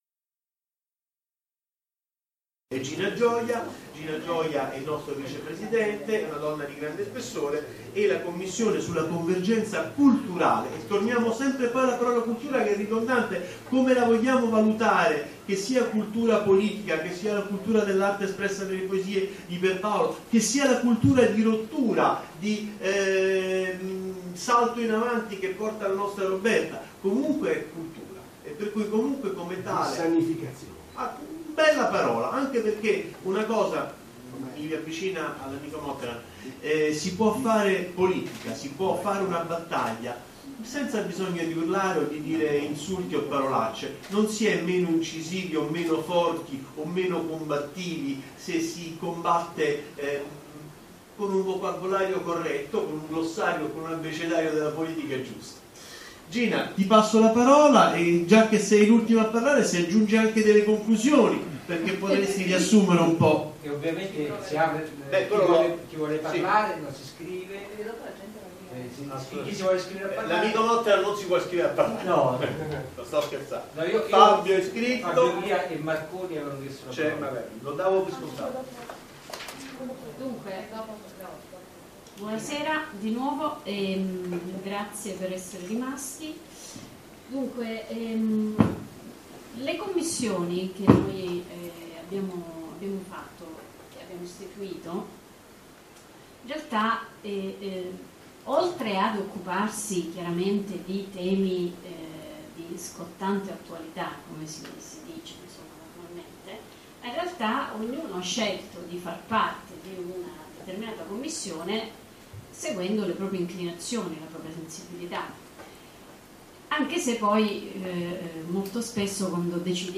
Assemblea Congressuale dell'Associazione di Cultura Politica “il Cantiere”, 25 gennaio 2015 Roma presso il River Chateau Hotel: